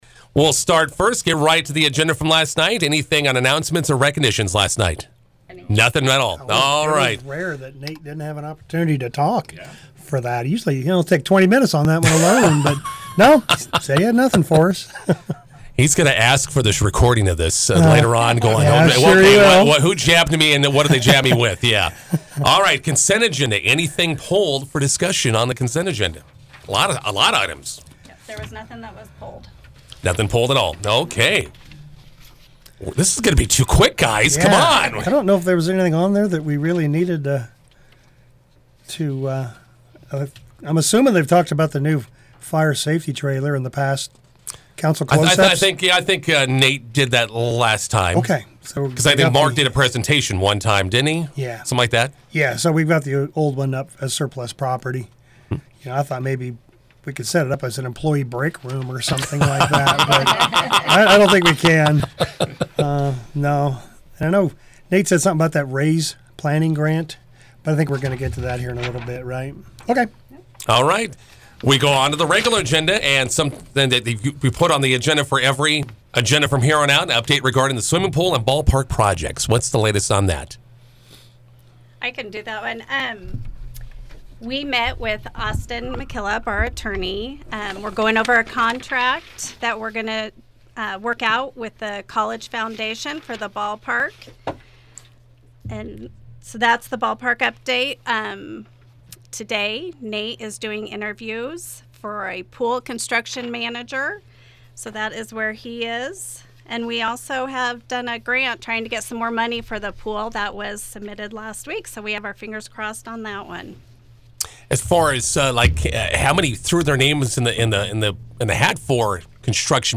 INTERVIEW: McCook City Council meeting recap